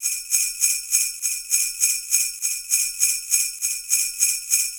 Index of /bg3/sound/ambience
bells.wav